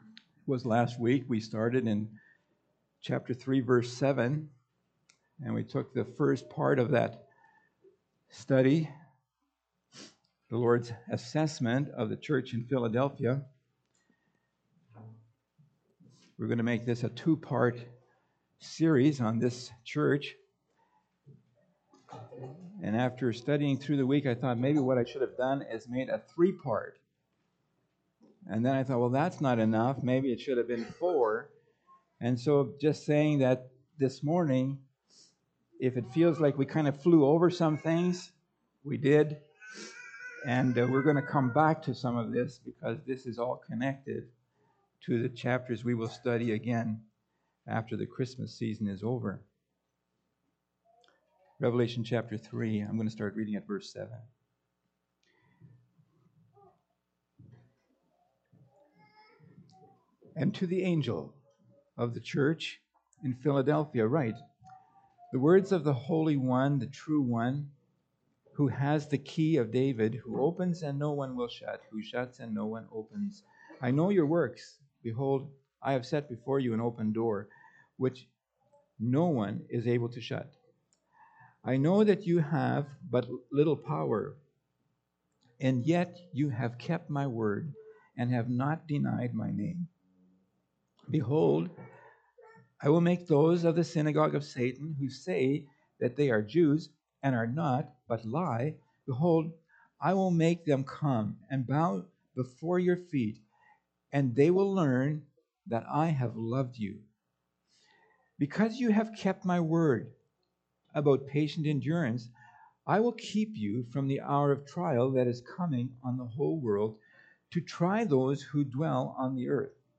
Pulpit Sermons Key Passage: Revelation 3:7-13 https